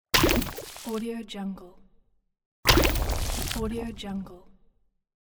دانلود افکت صدای پاشیدن آب
Sample rate 16-Bit Stereo, 44.1 kHz
Looped No